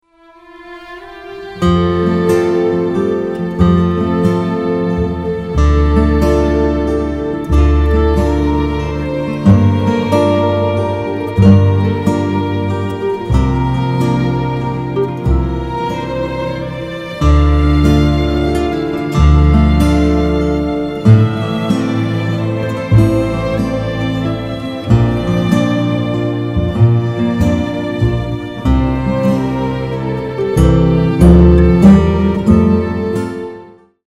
(بی کلام) محزون و سوزناک
به مناسبت ایام سوگواری حسینی